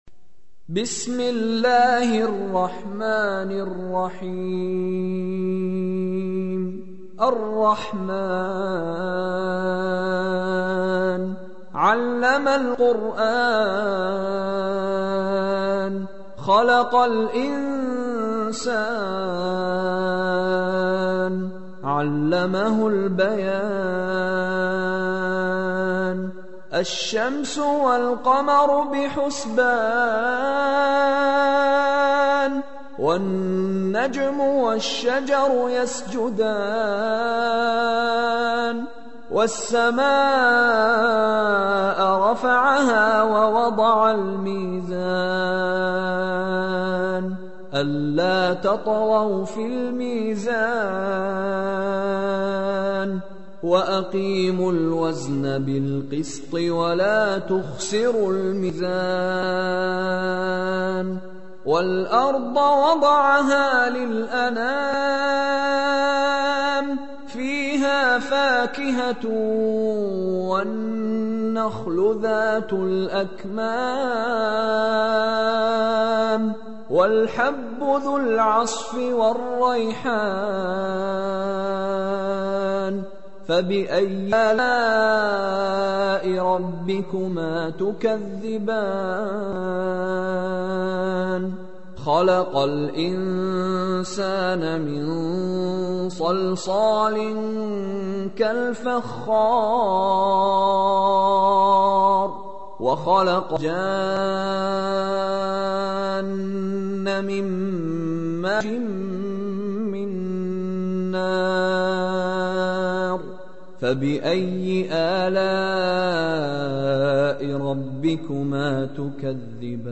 Чтение Корана > МИШАРИ РАШИД